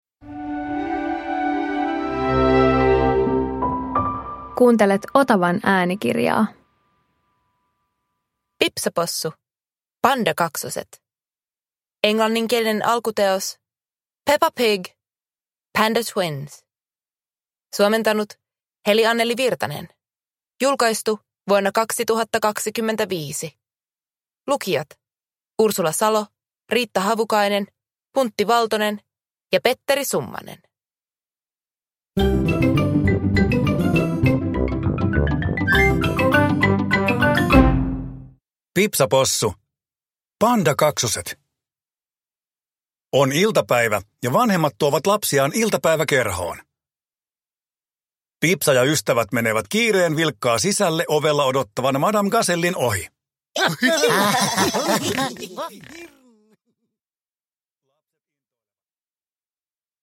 Pipsa Possu - Pandakaksoset (ljudbok) av Various